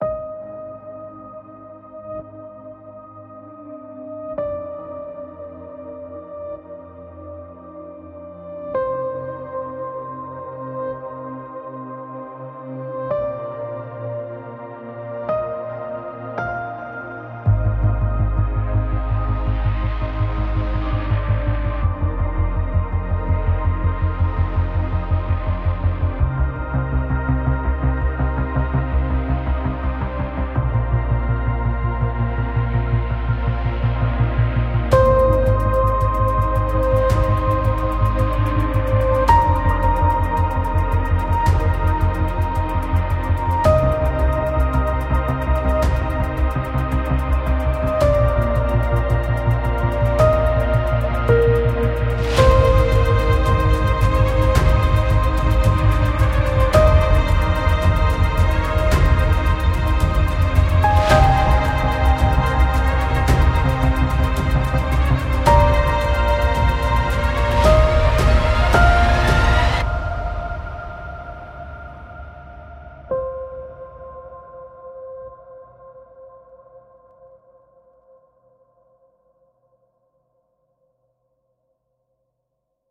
无论您是想通过数百个预设保持简单，还是深入表面雕刻自己的东西，这款乐器都充满了高清声音，一定会激发您的创造力。